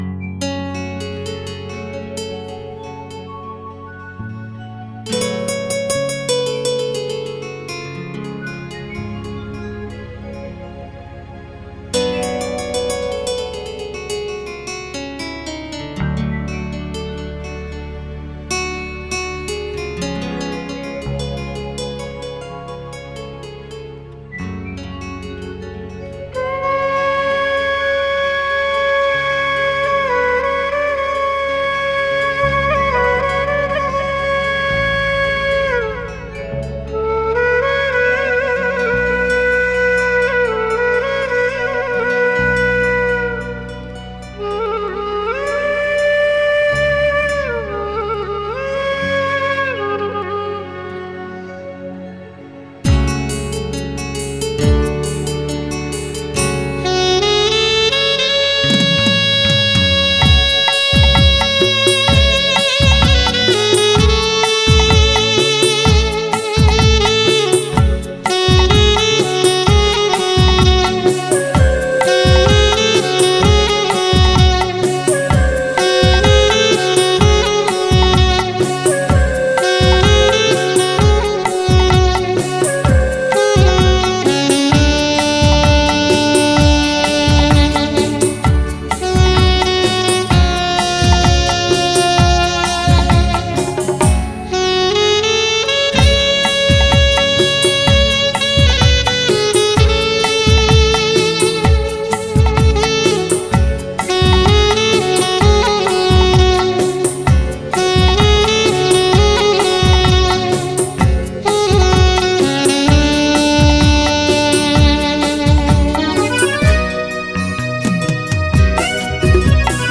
Category: Odia Karaoke instrumental Song